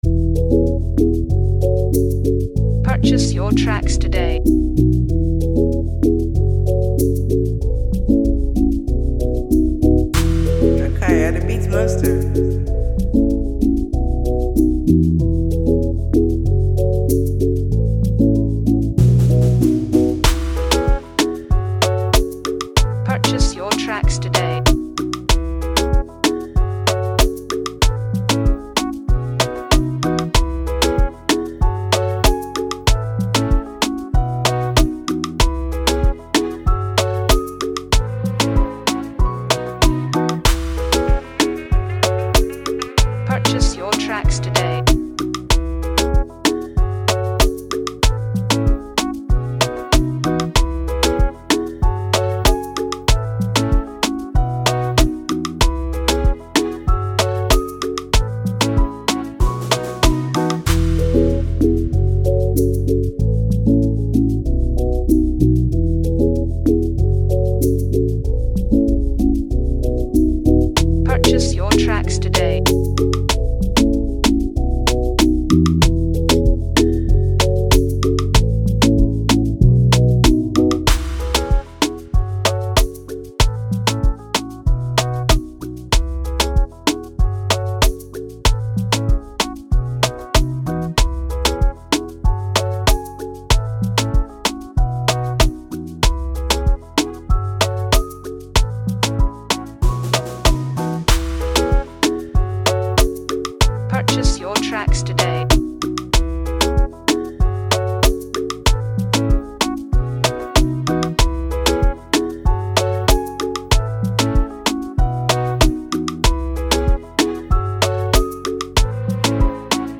a mesmerizing free new afrobeat instrumental
type beat